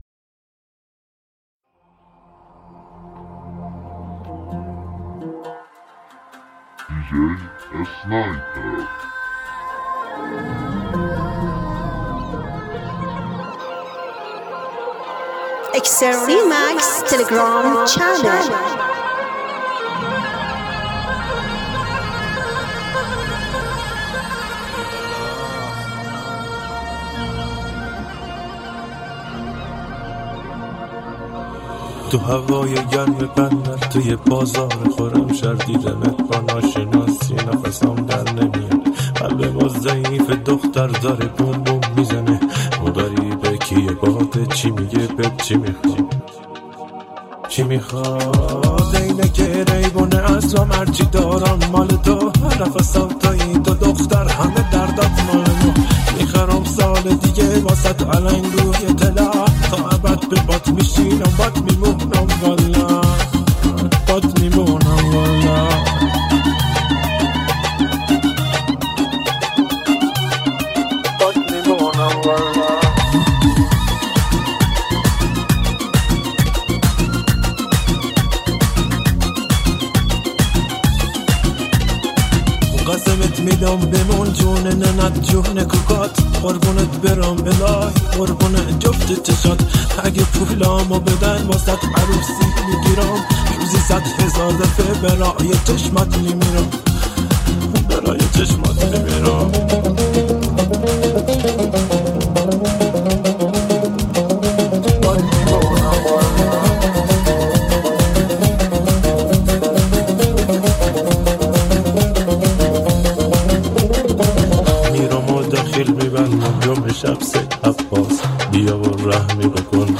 با لهجه آبادانی
اهنگ فوق العاده شاد شاد بندری جنوبی
آهنگ شاد بندری